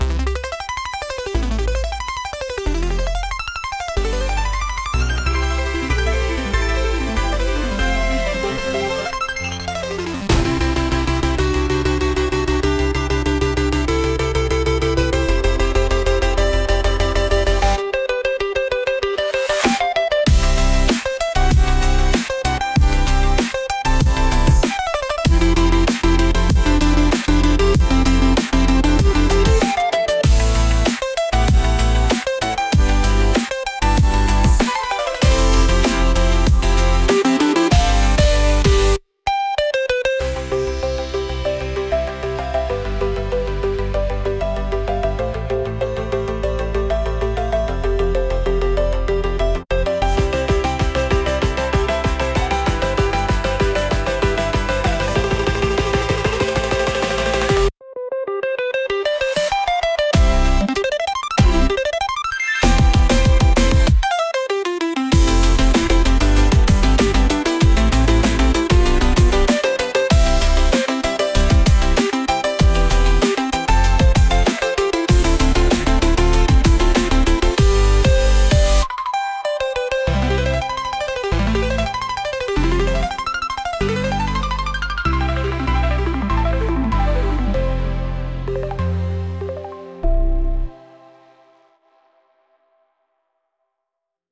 Genre: Electronic Mood: Energy Editor's Choice